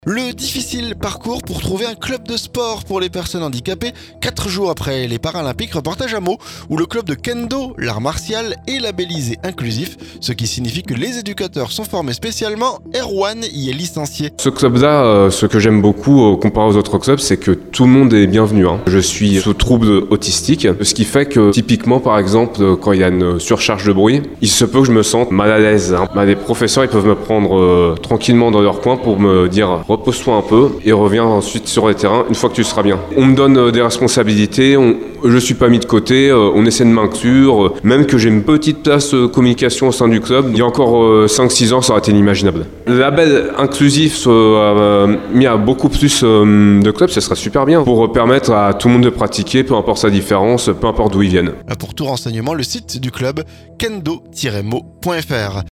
Le difficile parcours pour trouver un club de sport pour les personnes handicapées... 4 jours après les Paralympiques, reportage à Meaux, où le le club de kendo, l'art martial, est labellisé inclusif. Ce qui signifie que les éducateurs sont formés spécialement.